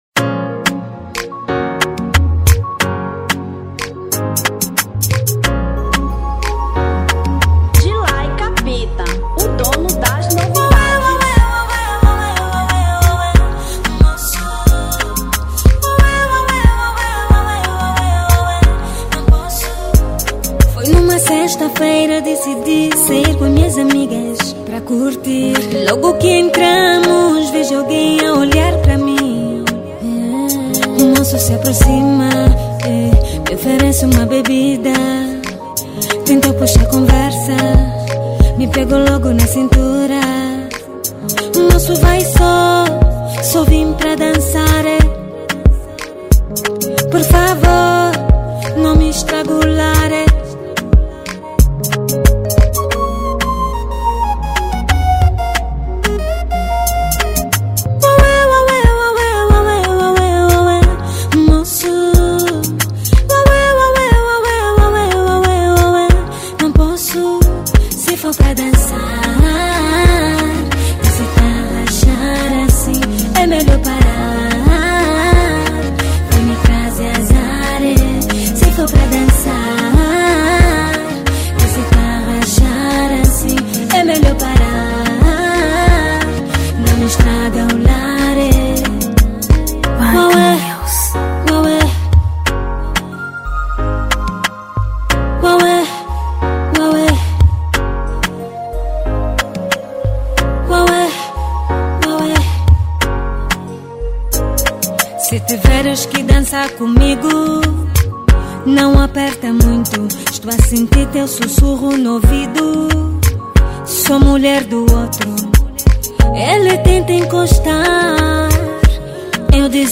Zouk 2017